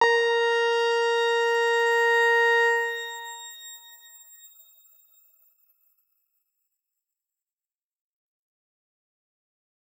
X_Grain-A#4-mf.wav